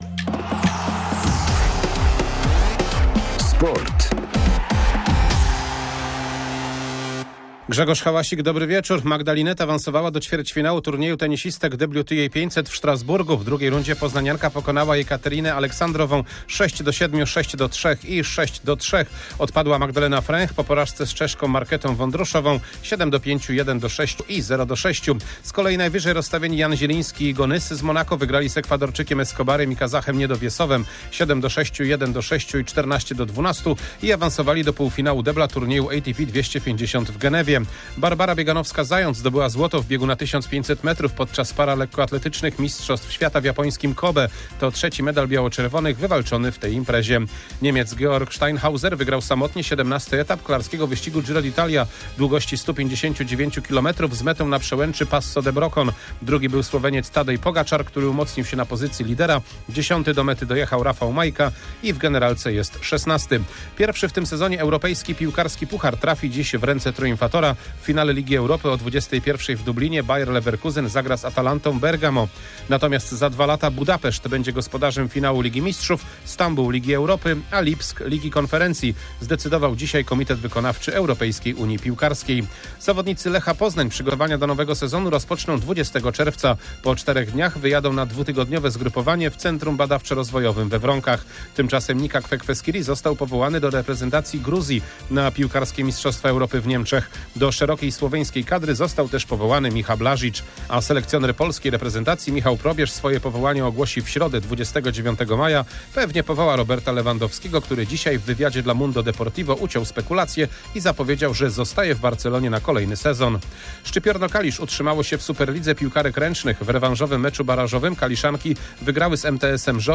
22.05.2024 SERWIS SPORTOWY GODZ. 19:05